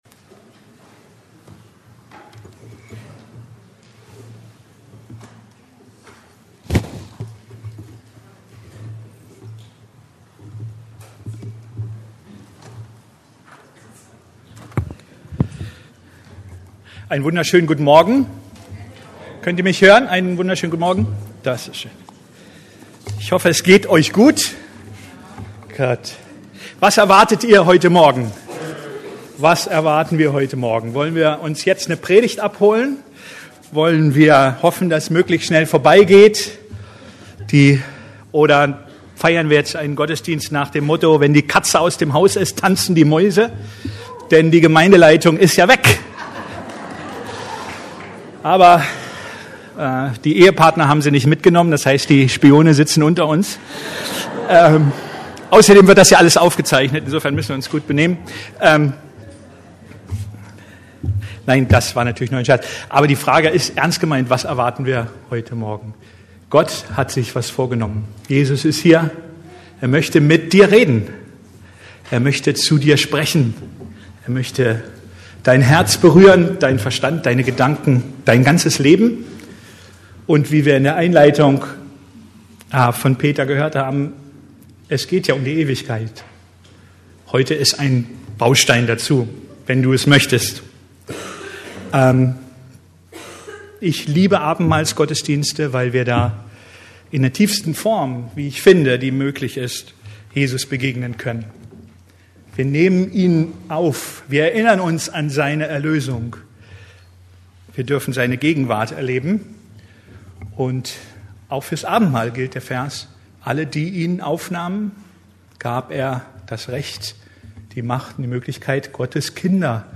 Geistliches Wachstum (für Dich und mich) ~ Predigten der LUKAS GEMEINDE Podcast